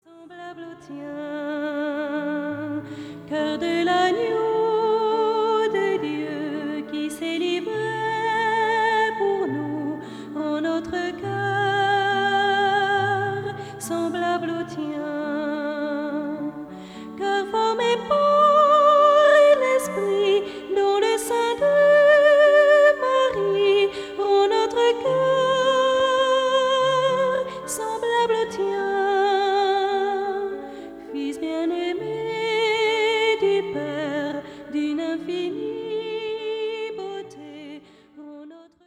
Interprétés par un choeur d'enfants